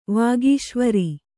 ♪ vāgīśvari